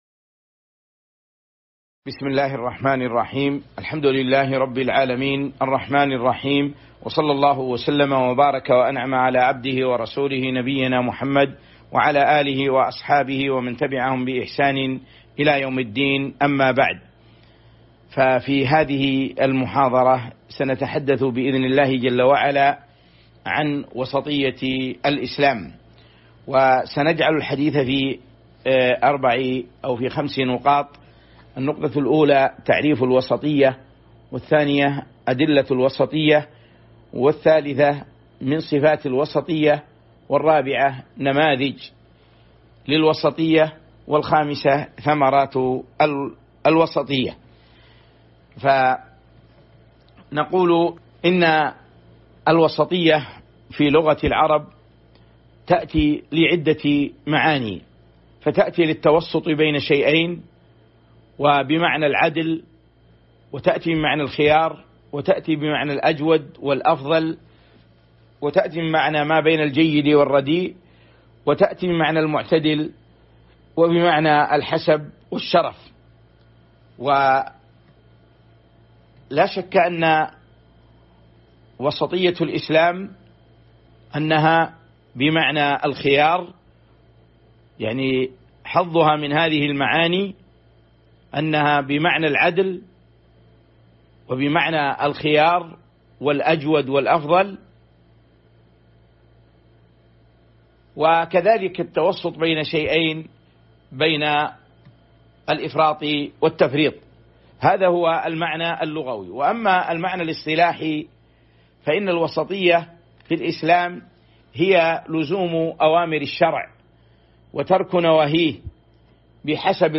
تاريخ النشر ٢٧ رمضان ١٤٤٢ هـ المكان: المسجد النبوي الشيخ